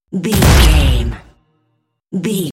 Dramatic drum hit electricity debris
Sound Effects
Atonal
heavy
intense
dark
aggressive